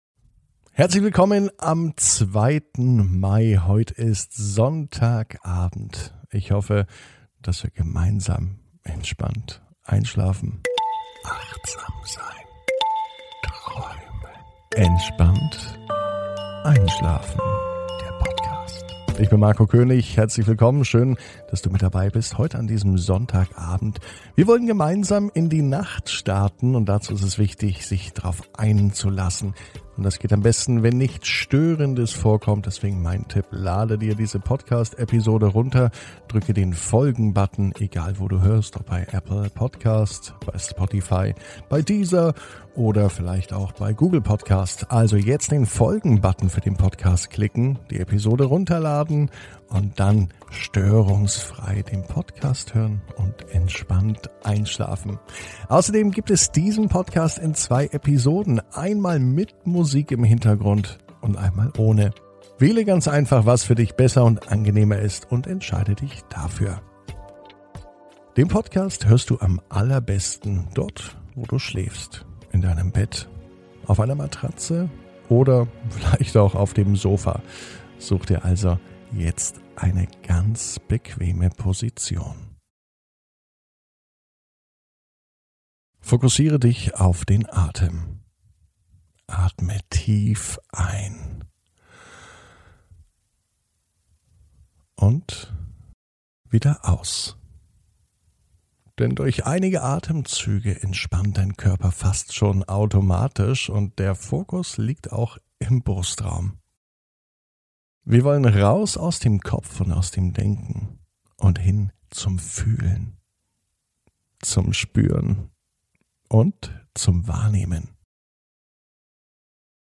(ohne Musik) Entspannt einschlafen am Sonntag, 02.05.21 ~ Entspannt einschlafen - Meditation & Achtsamkeit für die Nacht Podcast